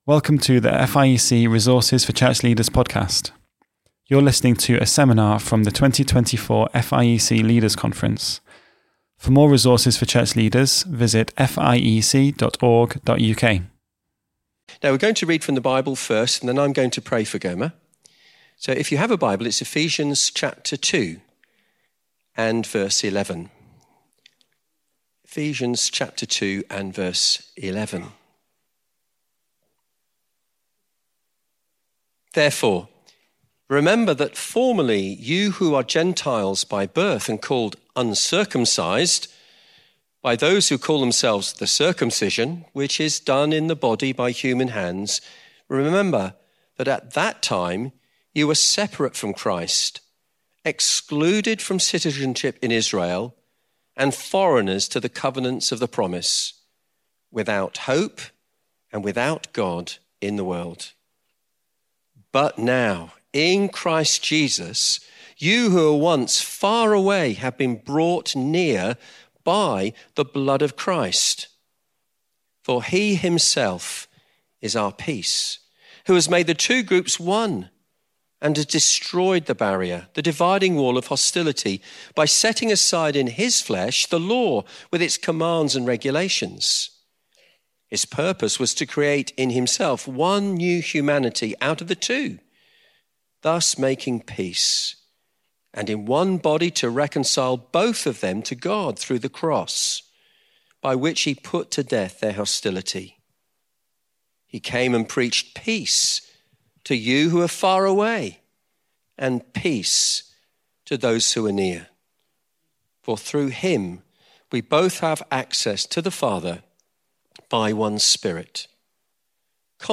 Shepherding-Across-Cultures-FIEC-Leaders-Conference-2024.mp3